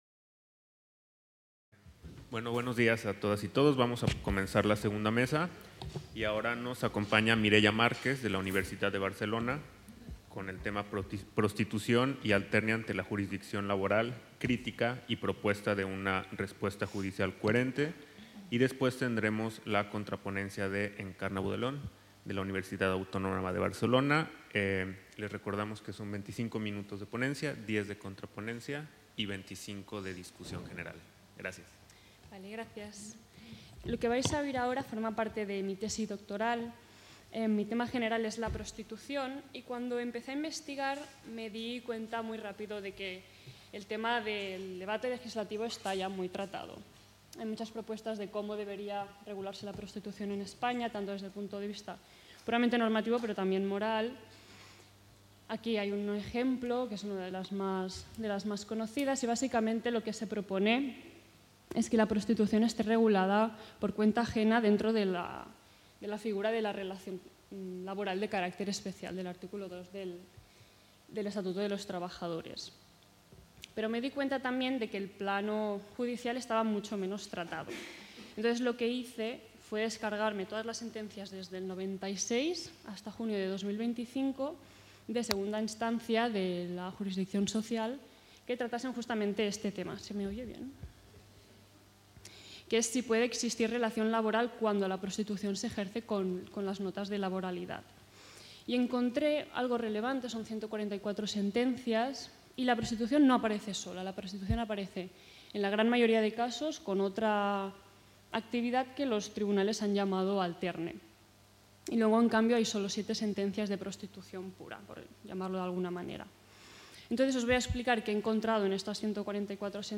The Chair of Legal Culture of the UdG organizes the II Catalan Interuniversity Seminar on Philosophy of Law.